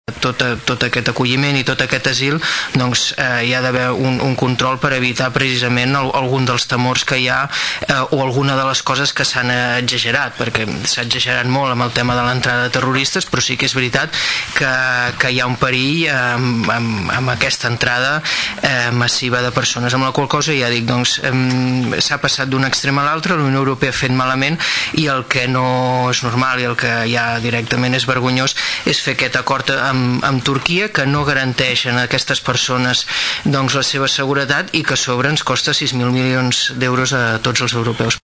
El regidor del Partit Popular, Xavier Martin creu que la Unió Europea no ha actuat correctament en la gestió d’aquesta problemàtica.